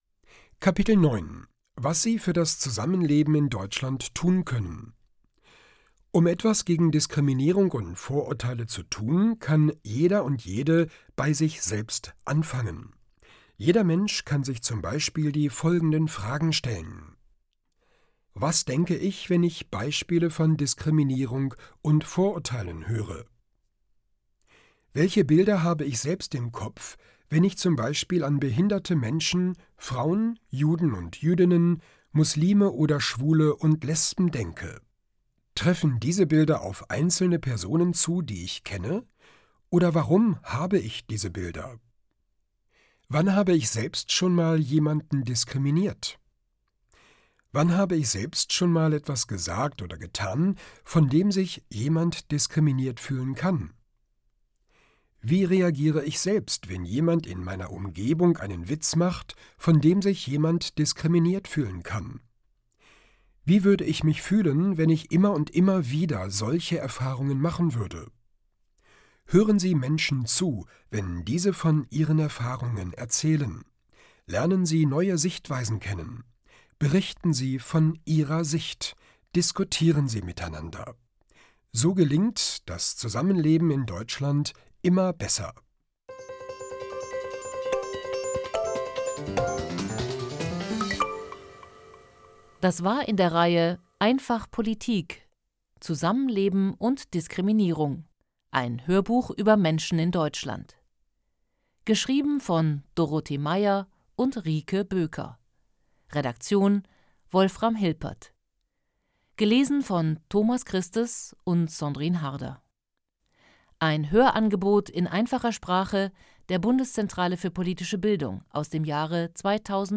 Kapitel 9: Was Sie für das Zusammenleben tun können Hörbuch: „einfach POLITIK: Zusammenleben und Diskriminierung“
• Produktion: Studio Hannover